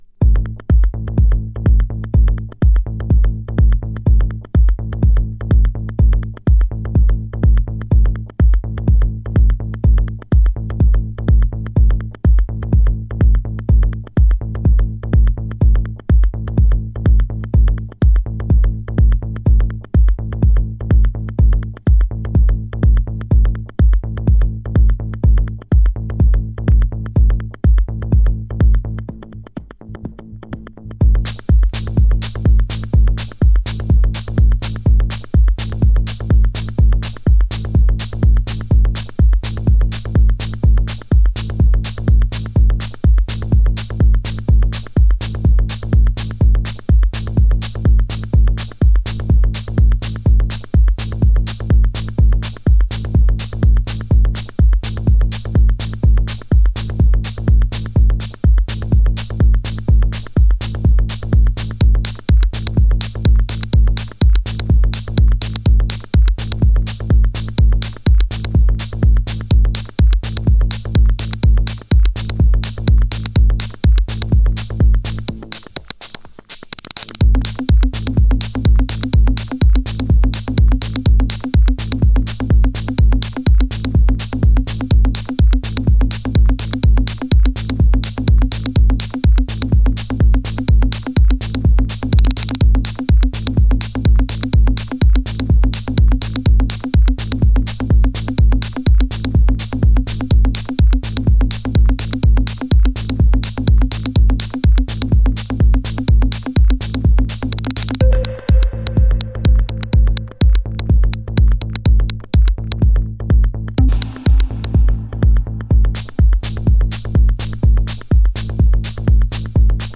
( 19M 2F - COMPLETE - Techno 2008 )